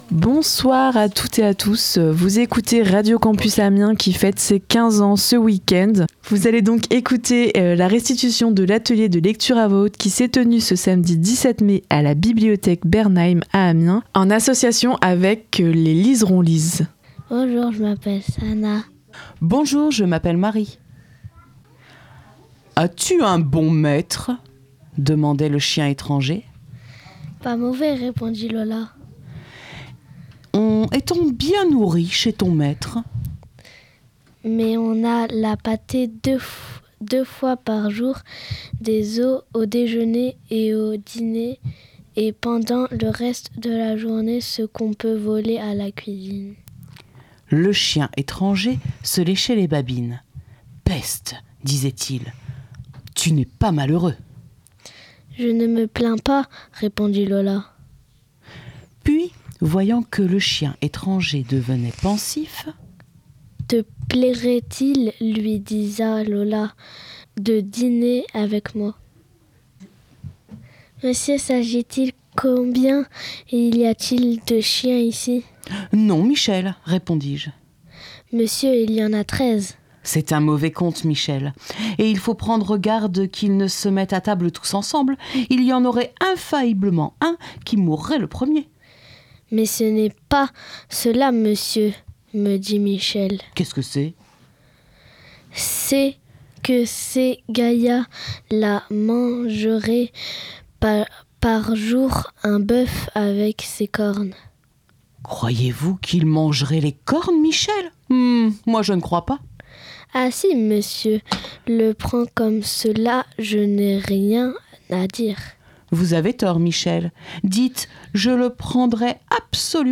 [15 ANS CHEZ CITE CARTER] [DE PAGES EN ONDES] Lecture sensible à voix haute (part. 1) - Radio Campus Amiens - 87.7 FM
Ce samedi 17 tout l’après midi, l’association Les Liserons Lisent, en coopération avec la Bibliothèque Bernheim, a proposé aux visiteurs de choisir un texte court, de s’entraîner un moment, puis de l’enregistrer à voix haute.
15-ans-chez-cite-carter-de-pages-en-ondes-lecture-sensible-a-voix-haute-part-1.mp3